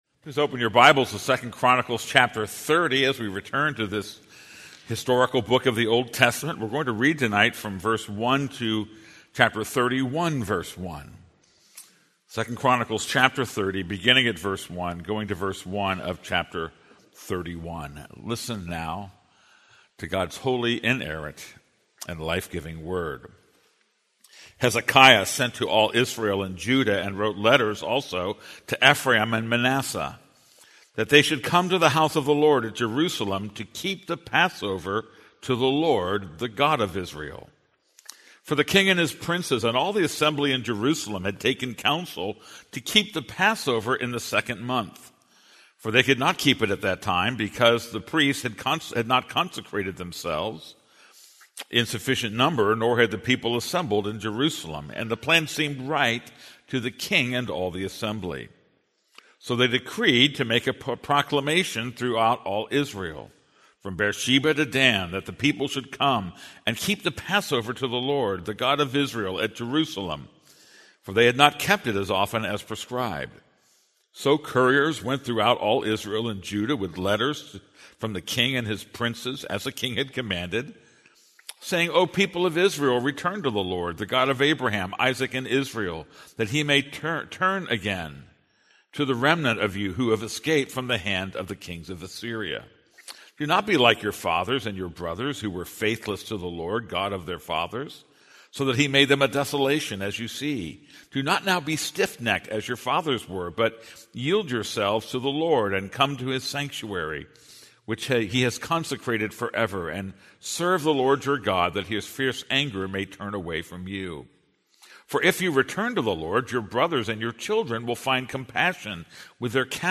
This is a sermon on 2 Chronicles 30:1-31:1.